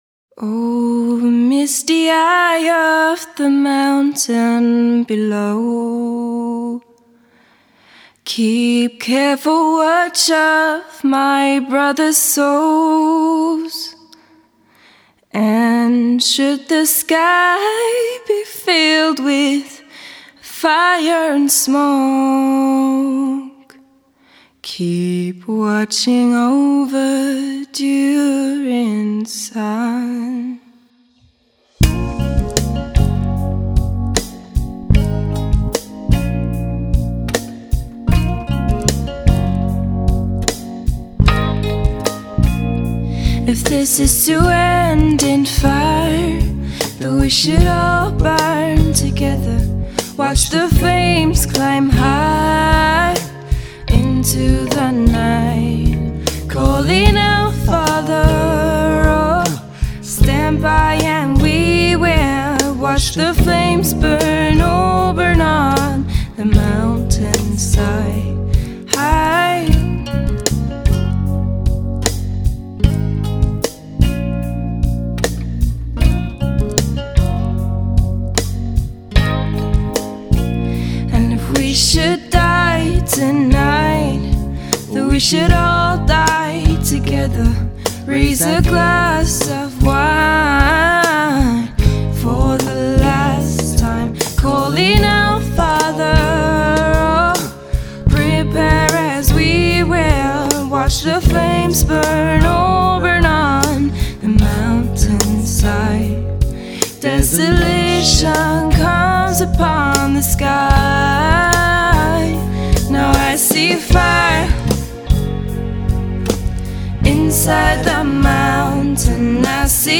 Rock/Pop-Band